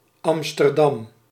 Amsterdam (/ˈæmstərdæm/ AM-stər-dam, UK also /ˌæmstərˈdæm/ AM-stər-DAM;[12][13] Dutch: [ˌɑmstərˈdɑm]
Nl-Amsterdam.ogg.mp3